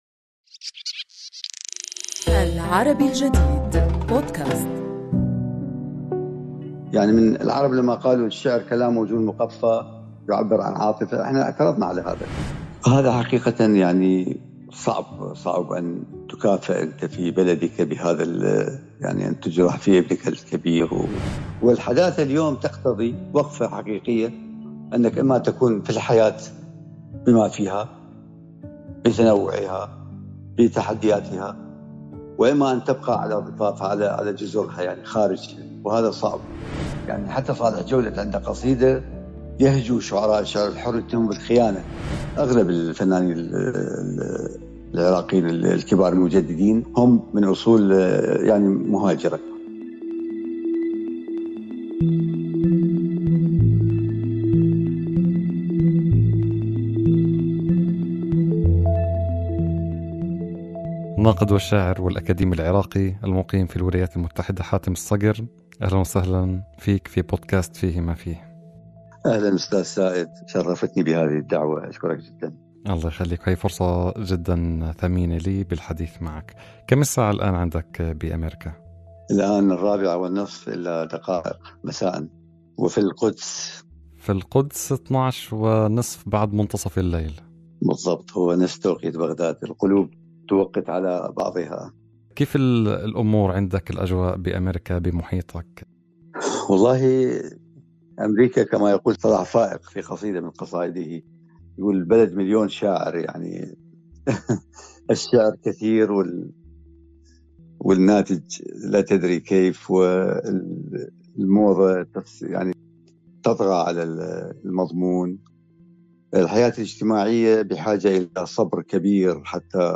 حوارنا اليوم مع الناقد والشاعر العراقي حاتم الصكر (1945)، يحدّثنا من مكان إقامته في الولايات المتحدة الأميركية عن قصته الأولى مع الشعر والنقد، وعن قصيدة النثر، وفصول من سيرته ومسيرته.